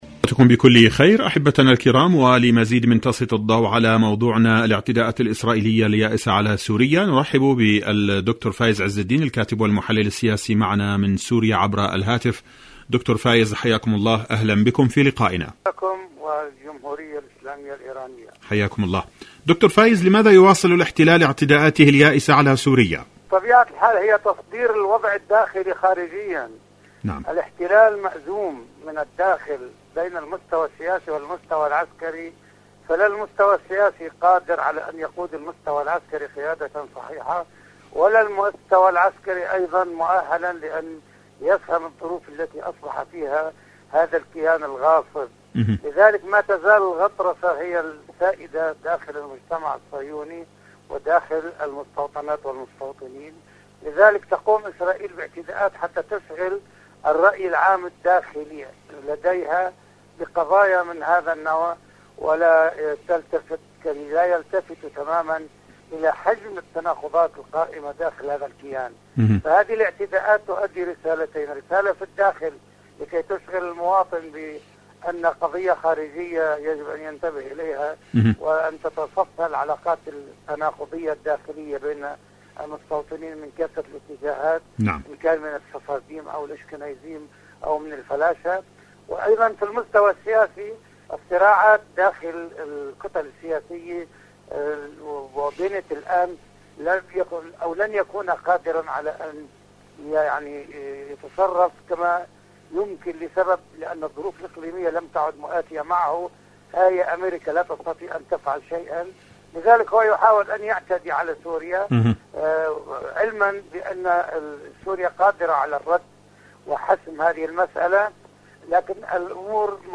مقابلات برامج إذاعة طهران العربية برنامج ارض المقاومة المقاومة محور المقاومة مقابلات إذاعية سوريا الاعتداءات الإسرائيلية اليائسة ارض المقاومة شاركوا هذا الخبر مع أصدقائكم ذات صلة يوم القدس، يوم الأمة لفلسطين..